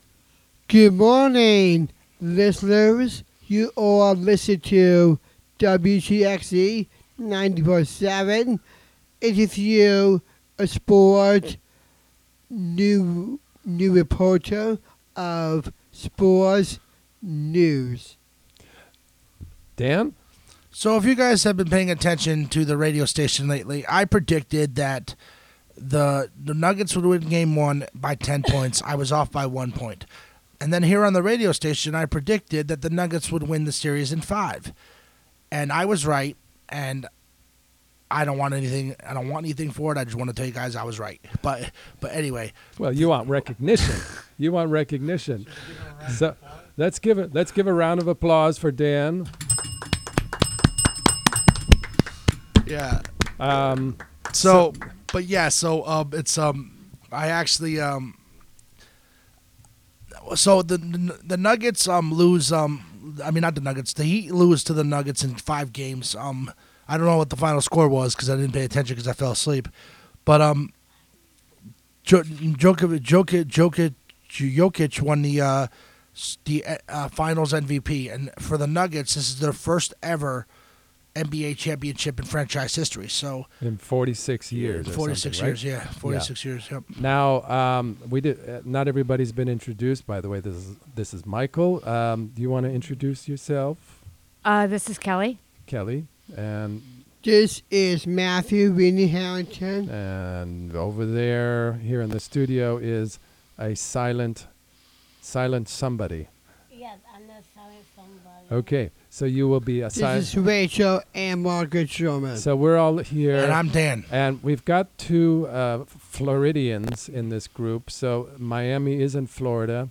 Camphill Sports Report: NBA Finals Recap (Audio)